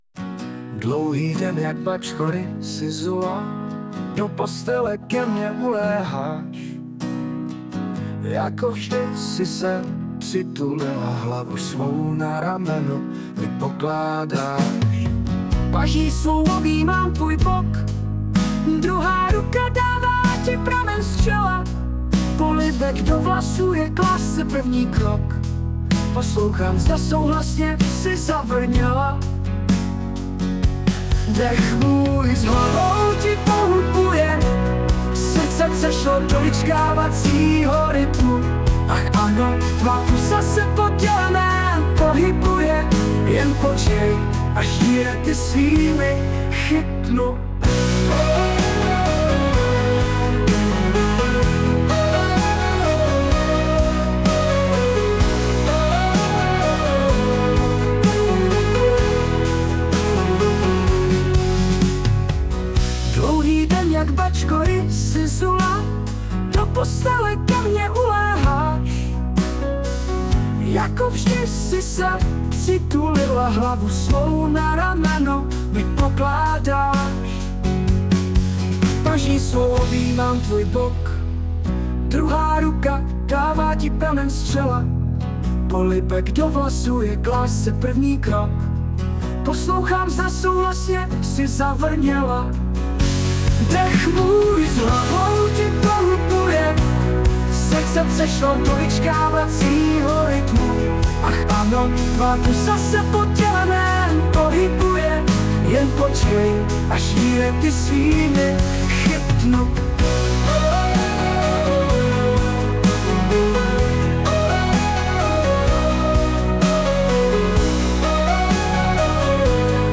Hudba, zpěv: AI
Romantická a chytlavá píseň.